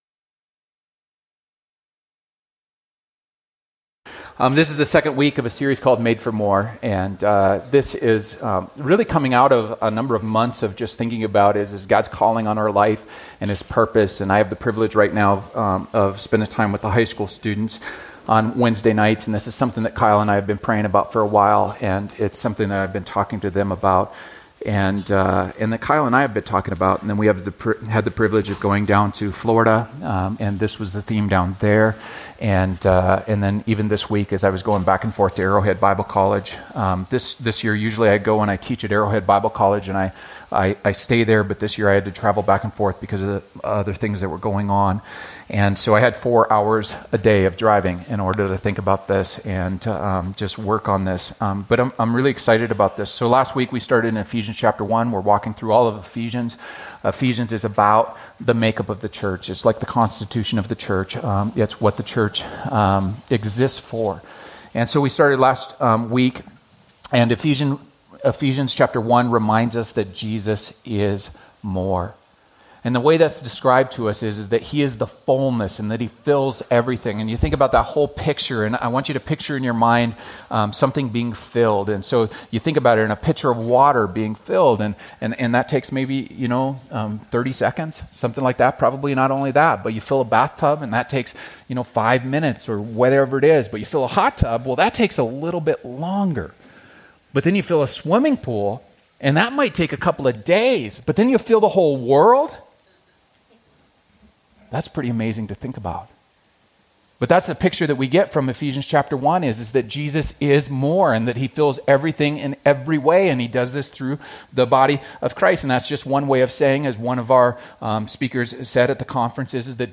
Join us for this sermon series!